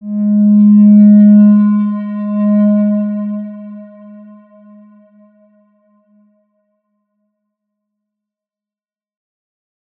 X_Windwistle-G#2-ff.wav